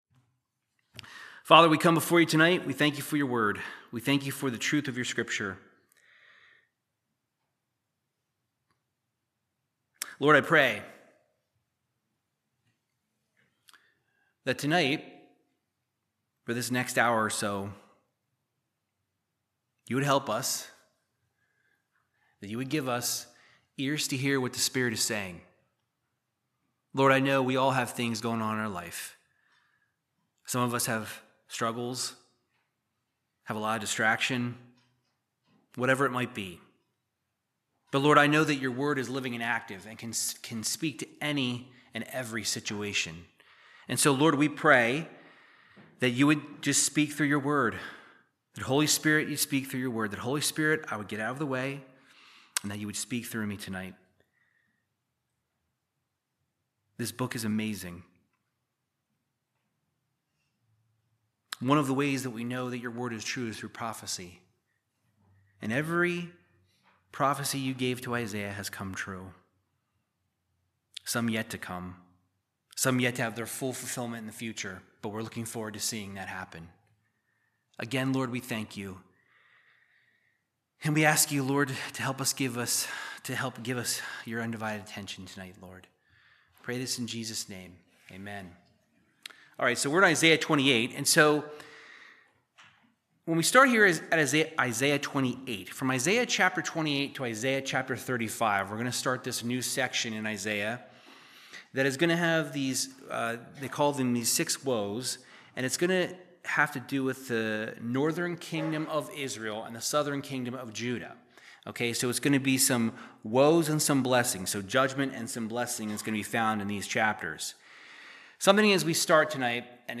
Verse by verse Bible teaching of Isaiah chapter 28